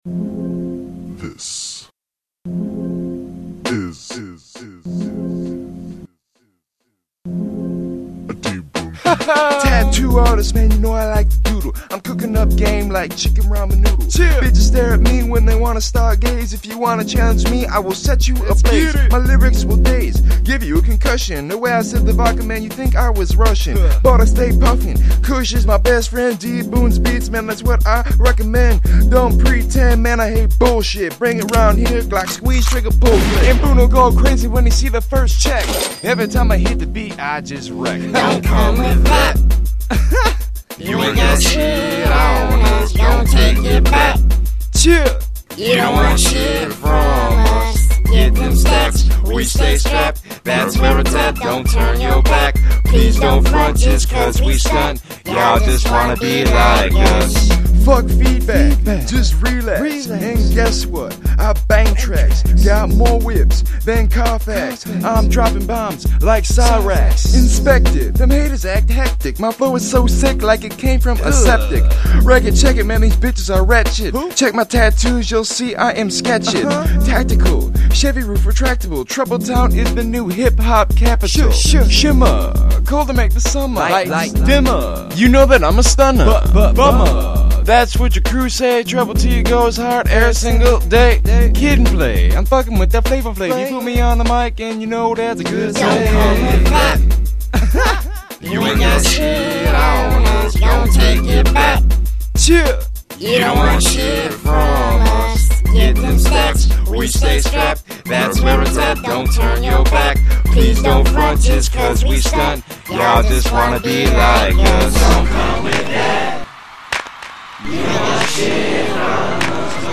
Rap
Hip-hop